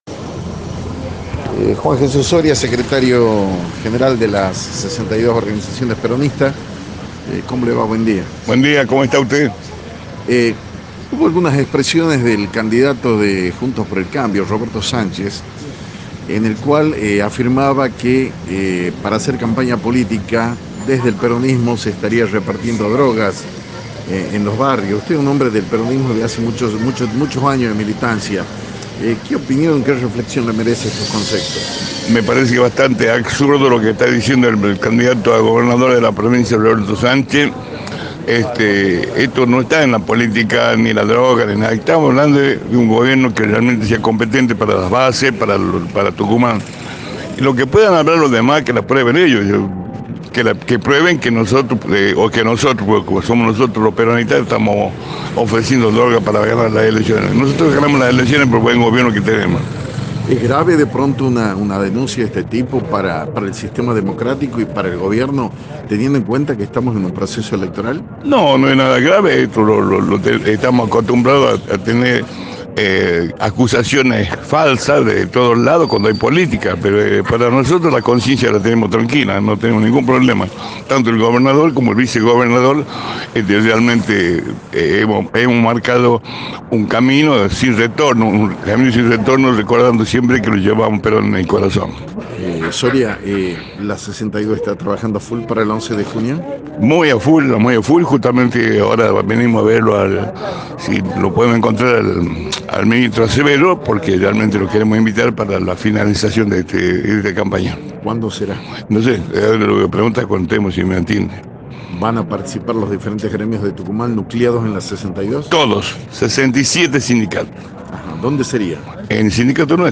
En diálogo con GN NOTICIAS